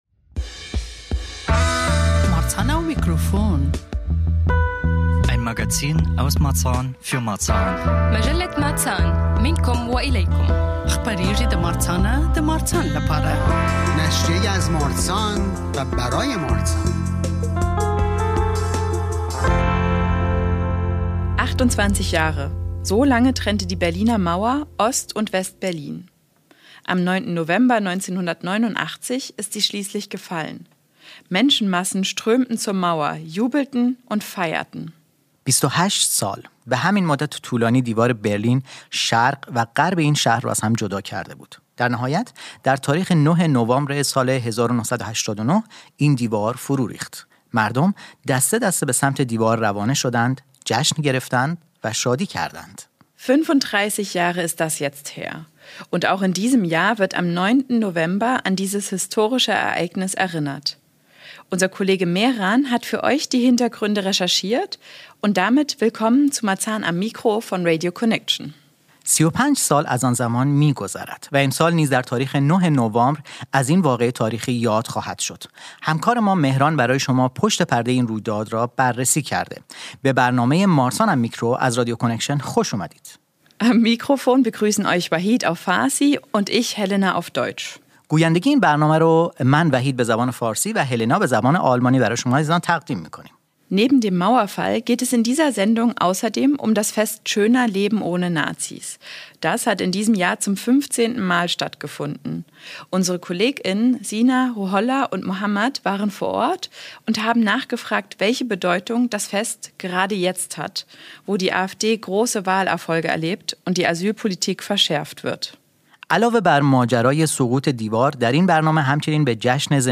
Wir waren vor Ort beim Fest und wollten von den Teilnehmenden wissen, wie sie aktuell die Stimmung erleben und welche Bedeutung das Fest „Schöner leben ohne Nazis“ für sie hat.